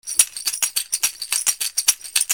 中には小石とボトルキャップ（王冠）が入っていて、独特な音色です。
マリ製 カシシ
カシシ1個の音